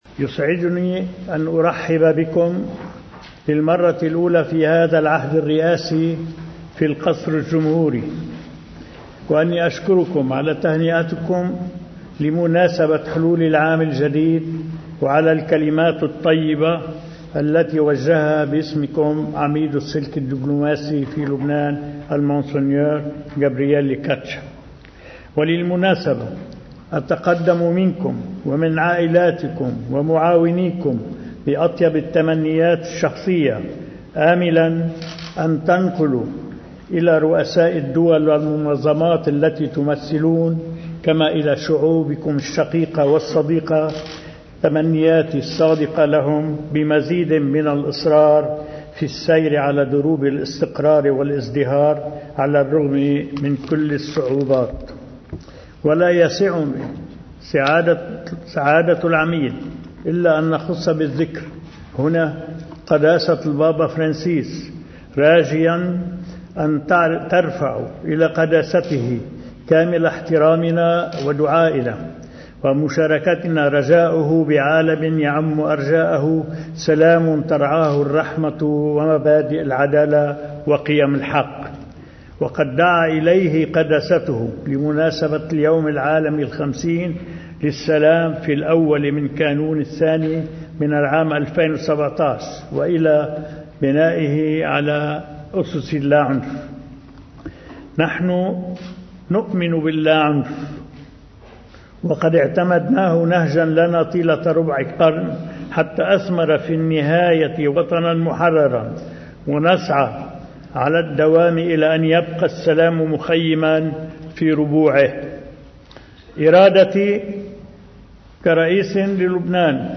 – إذا أردتم السلام، إطفئوا النار في مصادر اشتعالها، فالنار لا تنطفئ بنفسها طالما هناك حطب يوقد لها الرئيس عون لممثلي البعثات الديبلوماسية المعتمدة في لبنان، من قصر بعبدا: